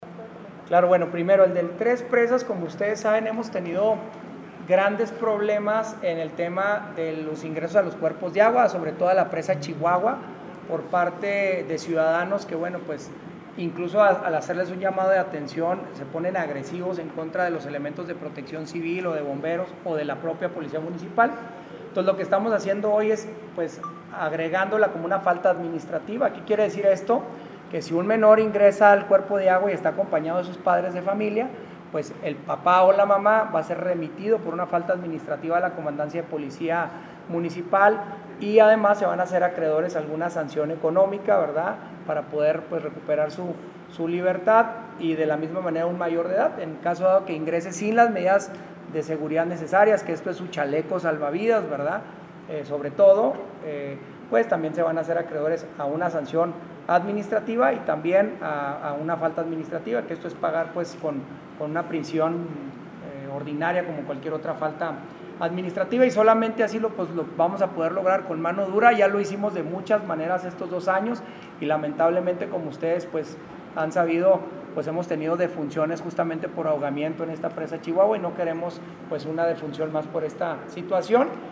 El alcalde Marco Bonila Mendoza sobre Parque Metropolitano Tres Presas.